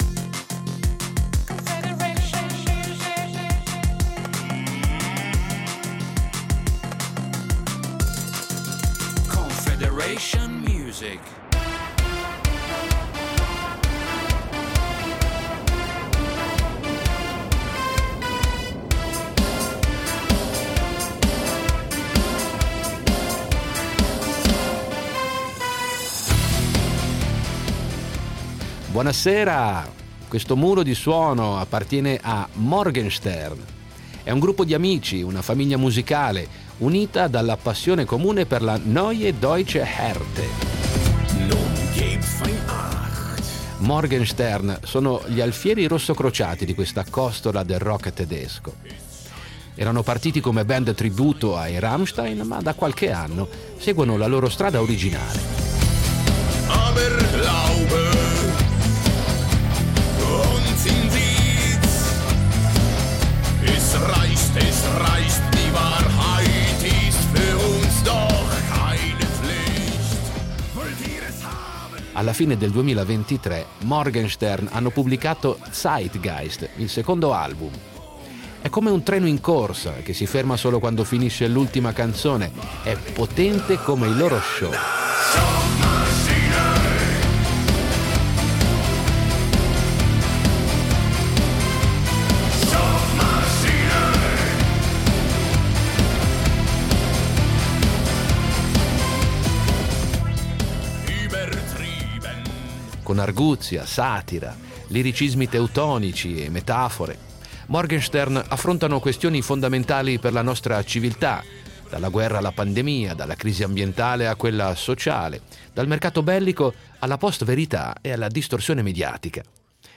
la Neue Deutsche Härte
È musica che ti fa muovere ma anche riflettere.